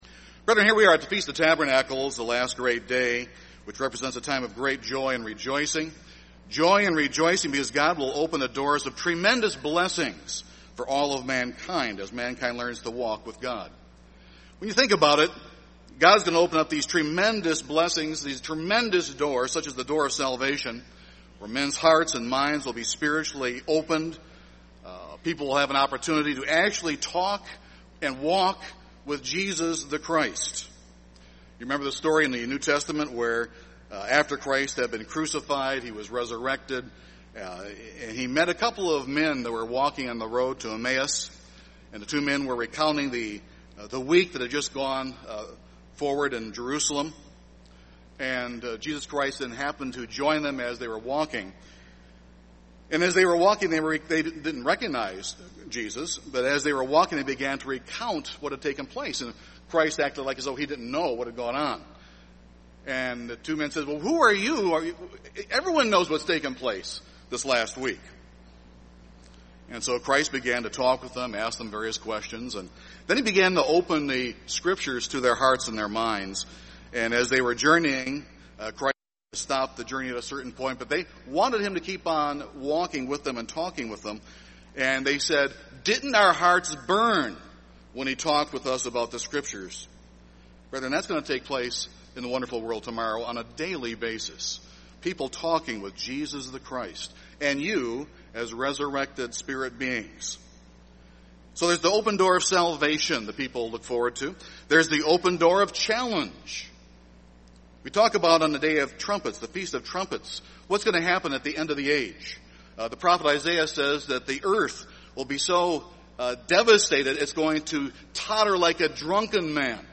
This sermon was given at the Wisconsin Dells, Wisconsin 2013 Feast site.